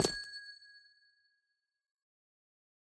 Minecraft Version Minecraft Version latest Latest Release | Latest Snapshot latest / assets / minecraft / sounds / block / amethyst / step8.ogg Compare With Compare With Latest Release | Latest Snapshot
step8.ogg